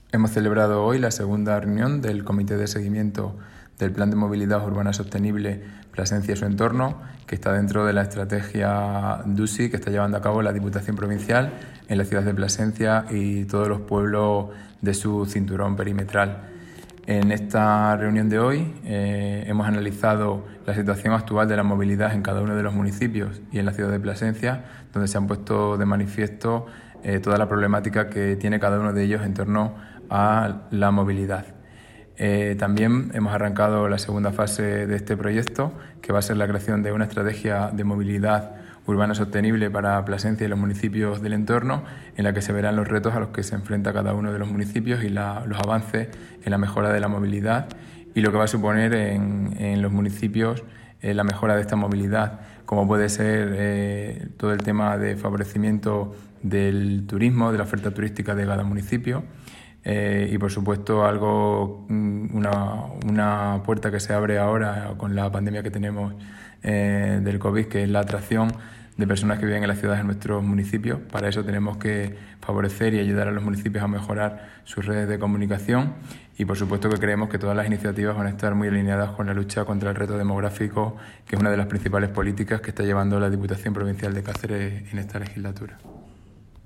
El diputado de Infraestructuras Inteligentes y Movilidad, Luis Fernando García Nicolás, ha intervenido en la Comisión de Seguimiento del Plan en la que se ha analizado el proceso participativo.
CORTES DE VOZ